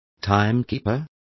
Complete with pronunciation of the translation of timekeeper.